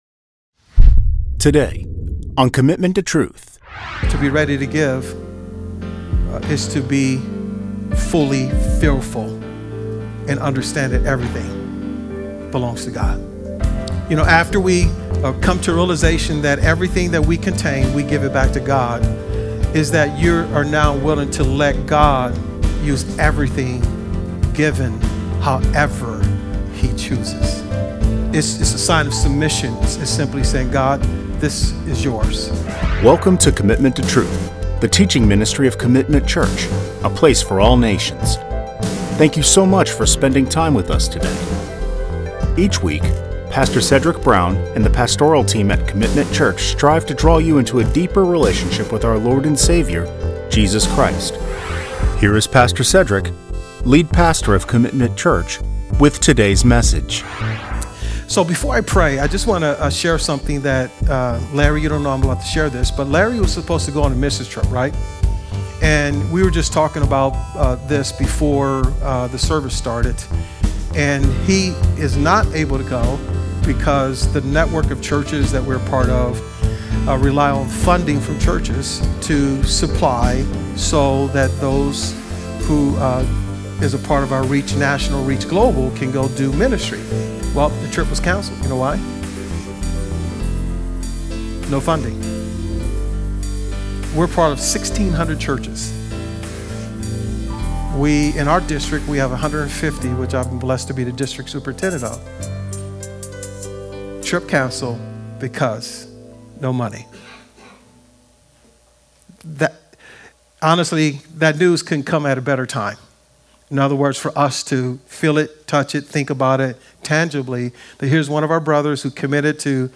Sermons | Commitment Community Church